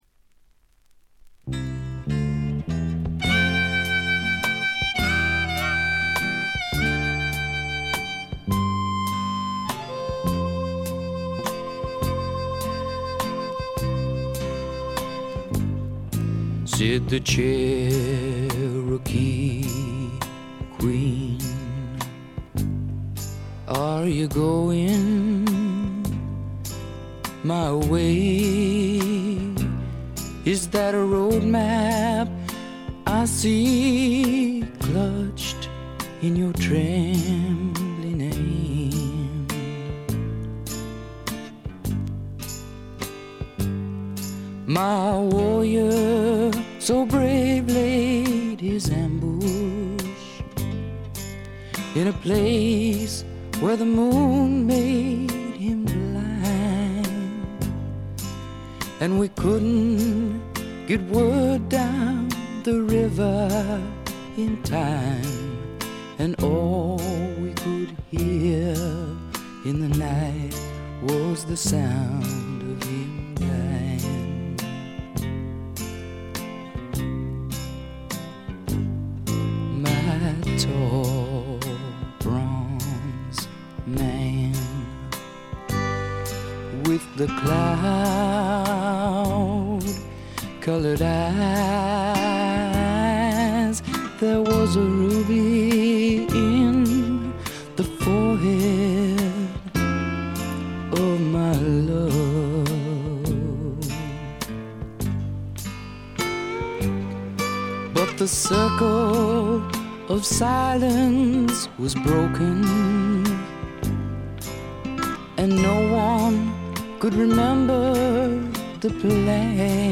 ごくわずかなノイズ感のみ。
試聴曲は現品からの取り込み音源です。
Harmonica [Harp]
Pedal Steel Guitar